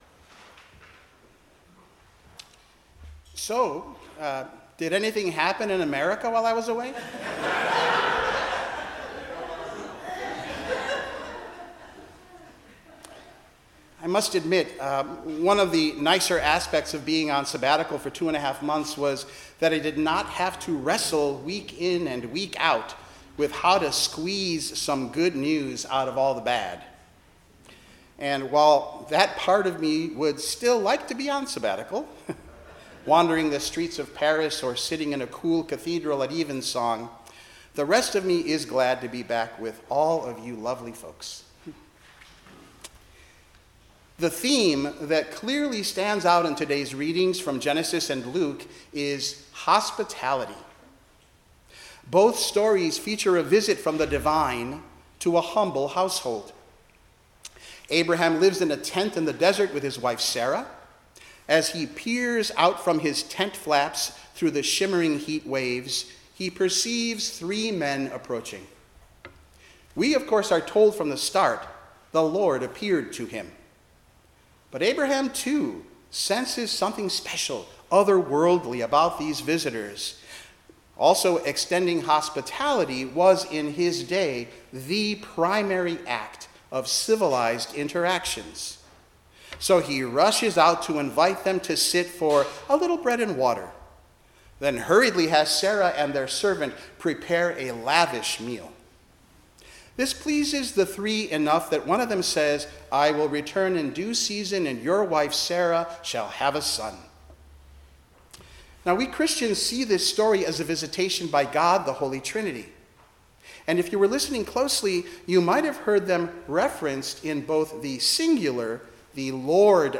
10:00 am Service